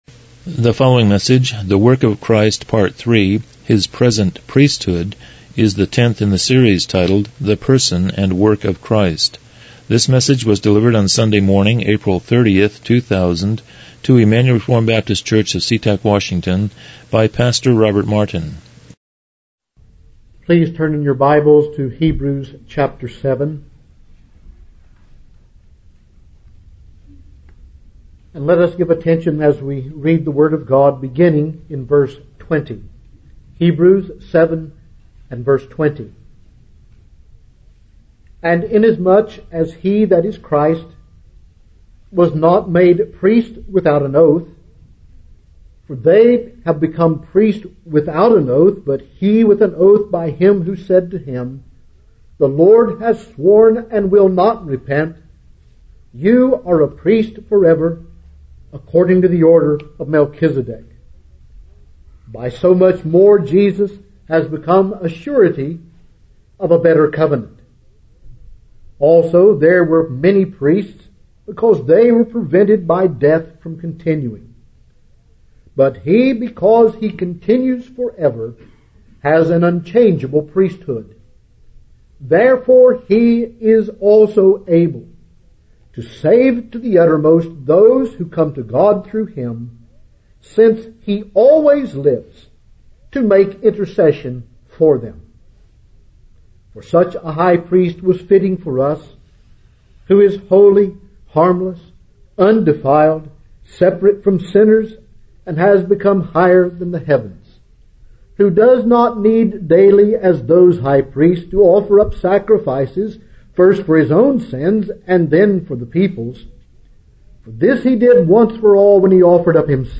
Series: The Person and Work of Christ Service Type: Morning Worship